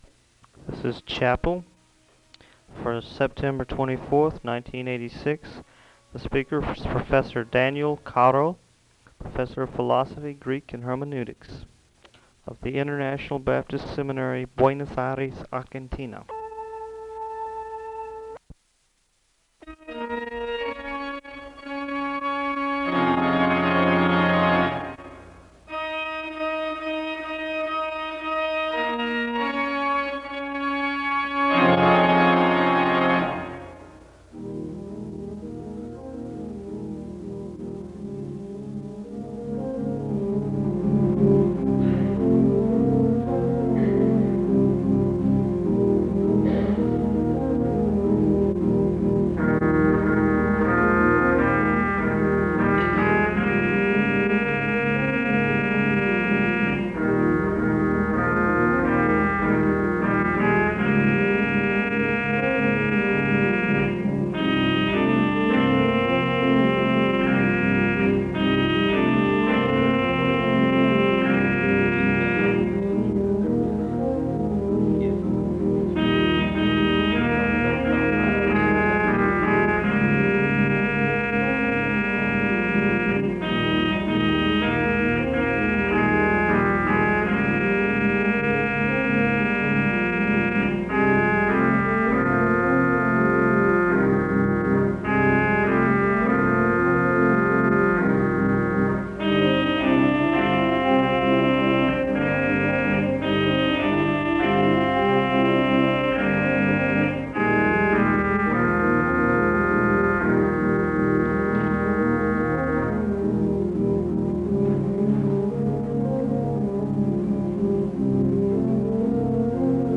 The service begins with organ music (0:00-2:29). There is a moment of prayer (2:30-4:49).
The choir sings an anthem (5:42-8:12).
The service closes with a benediction (30:22-30:49).